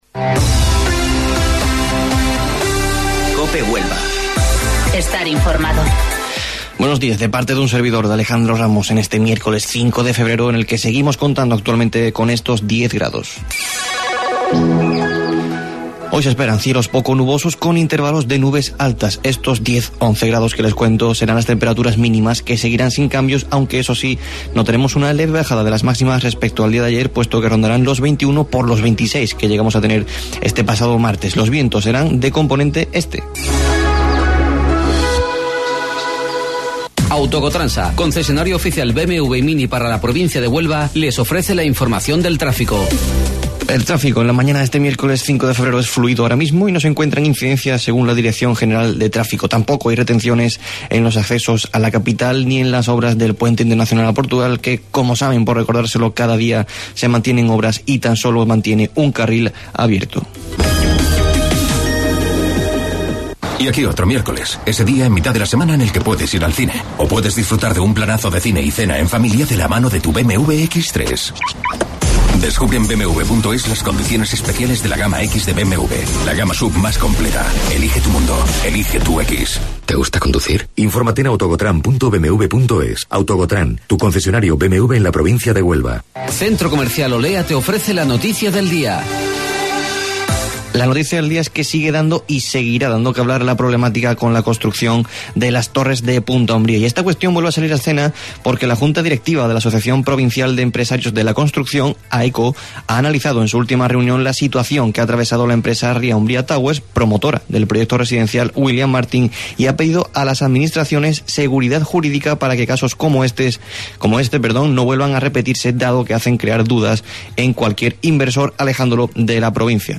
AUDIO: Informativo Local 08:25 del 5 de Febrero